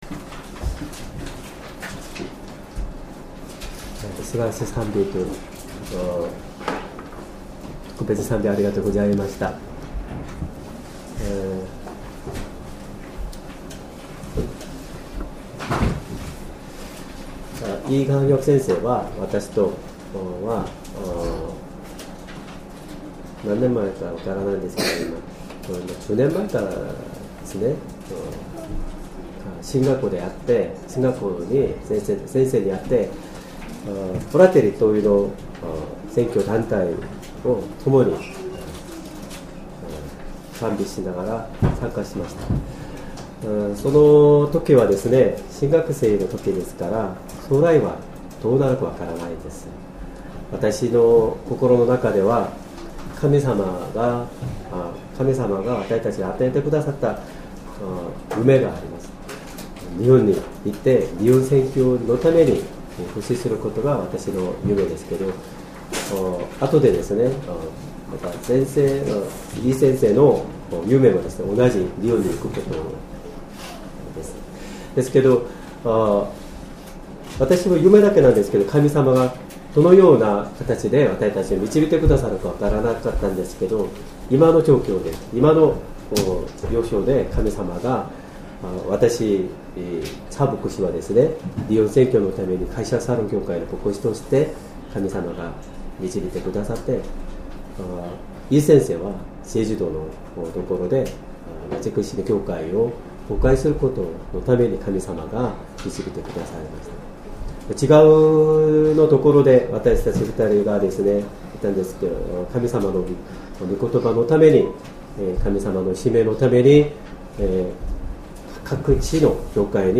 Sermon
2025年1月12日 主日礼拝 説教 「他人のための祈り」 聖書 創世記 18：21～31 18:21 わたしは下って行って、わたしに届いた叫びどおり、彼らが滅ぼし尽くされるべきかどうかを、見て確かめたい。」